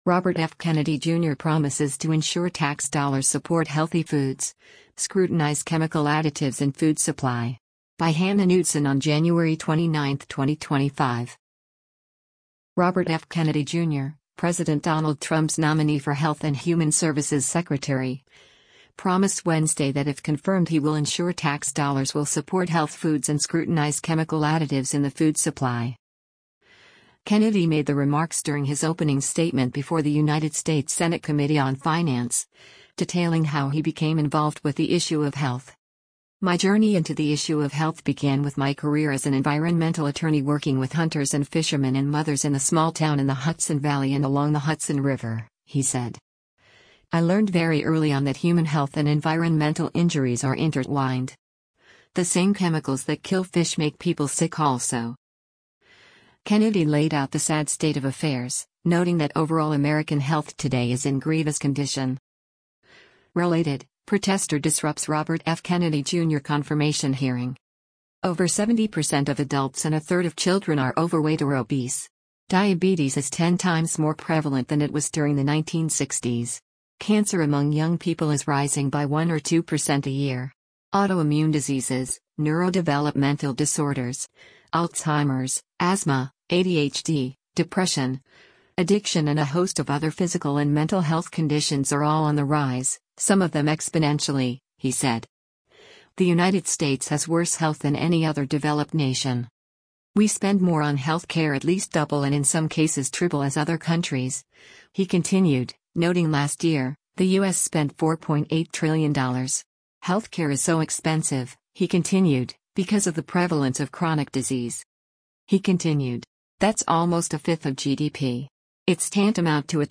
Kennedy made the remarks during his opening statement before the United States Senate Committee On Finance, detailing how he became involved with the issue of health.